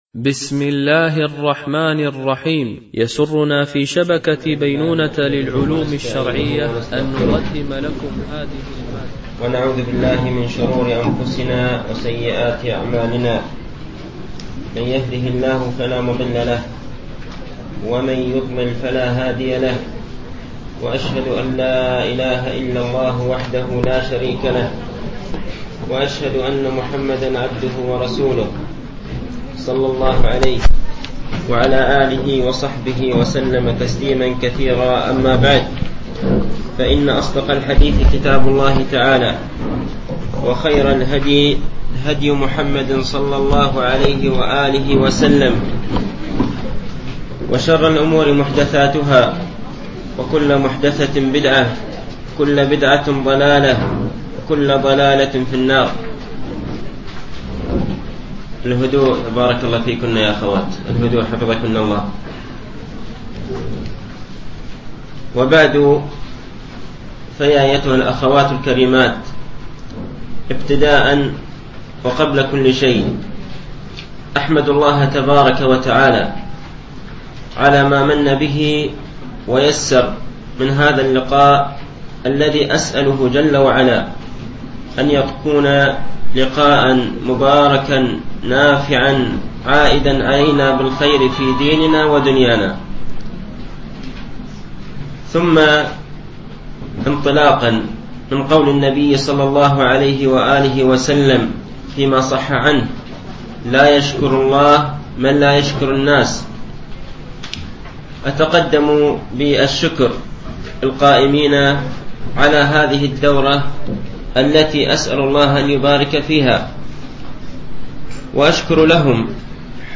شرح ستة أصول عظيمة مفيدة ـ الدرس الاول (التعريف بالرسالة وبيان أهميتها وشروحها)